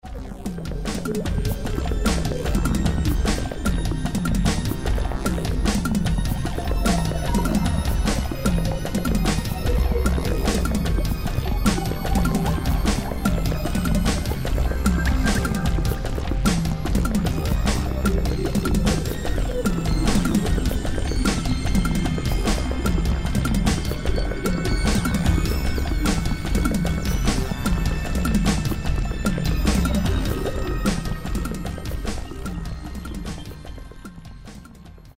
RAVE